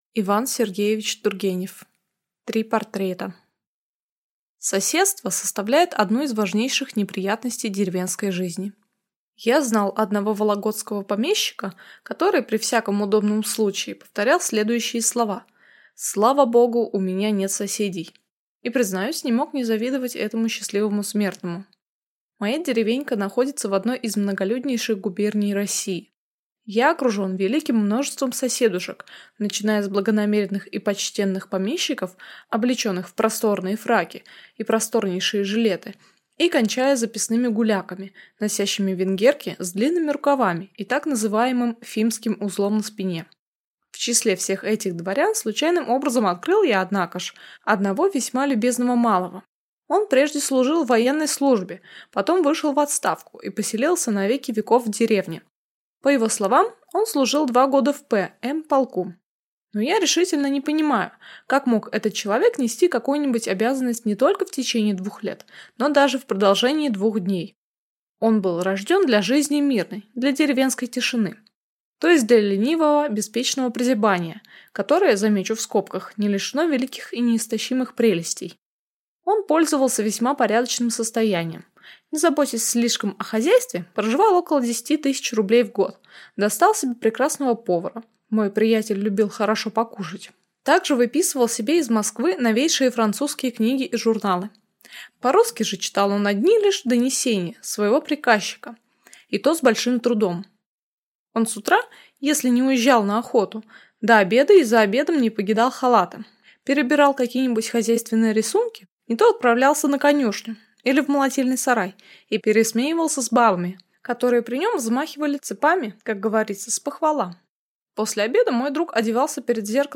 Аудиокнига Три портрета